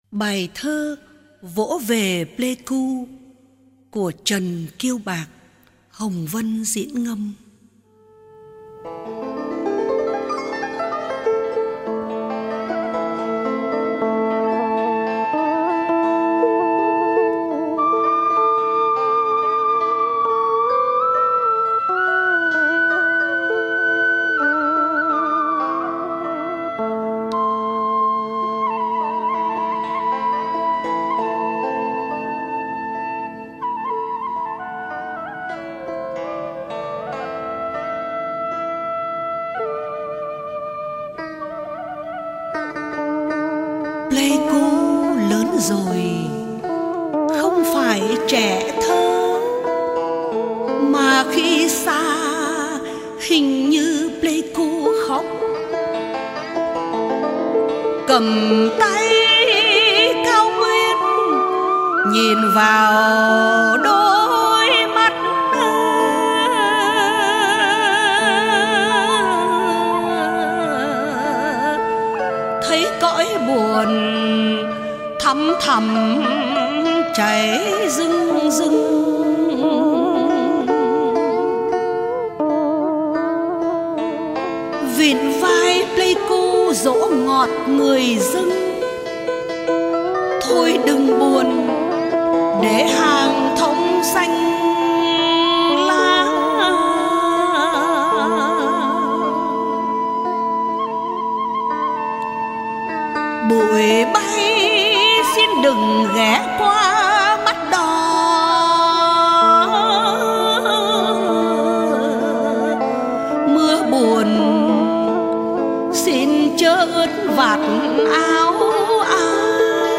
Ngâm Thơ